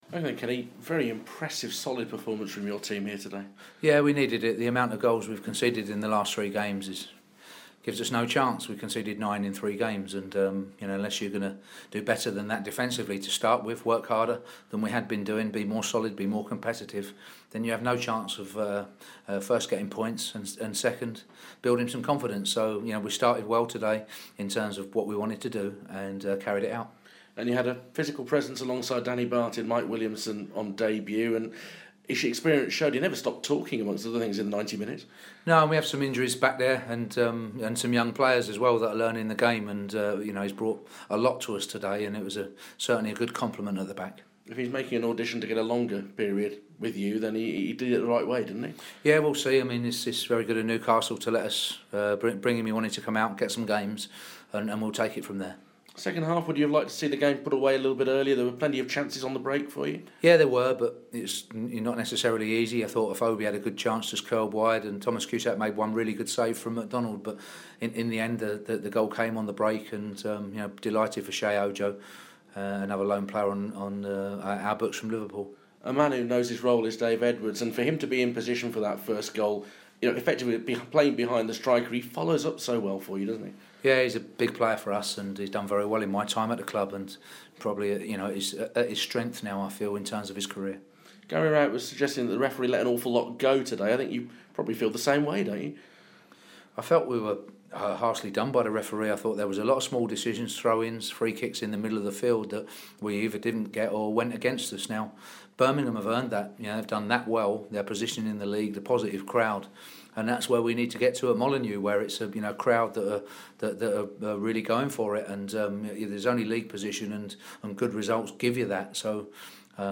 LISTEN: Wolves boss Kenny Jackett speaks to BBC WM after derby day victory